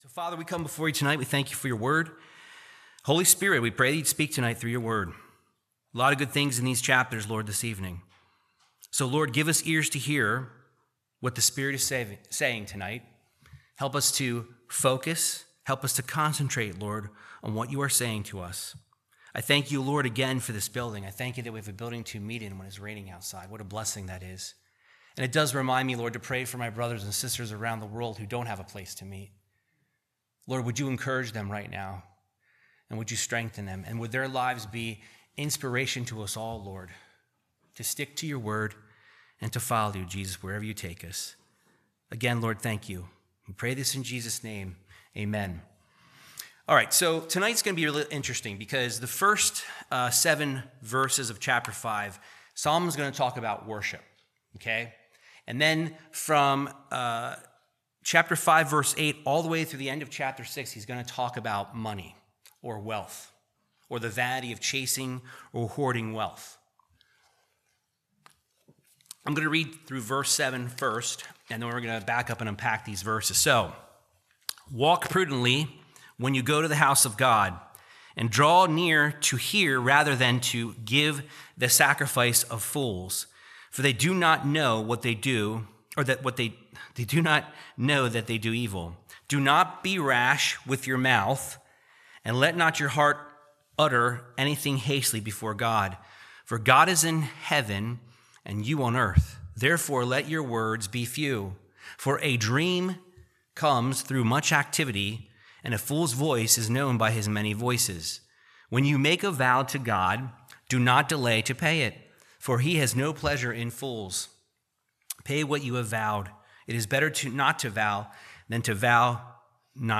Verse by verse teaching of Ecclesiastes 5-6